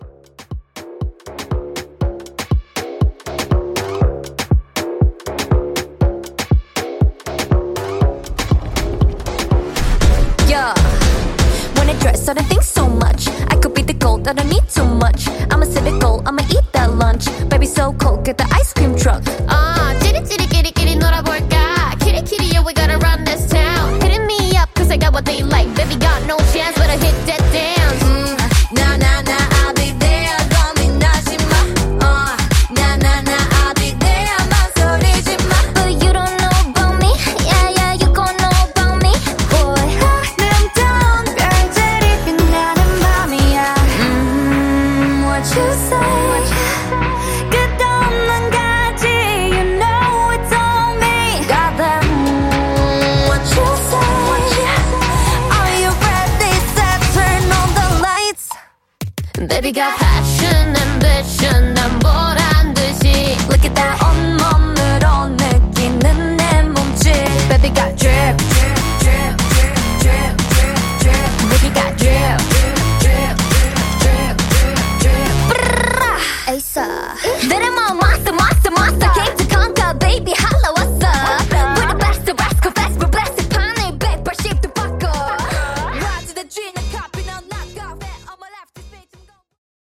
K-Pop In-Outro)Date Added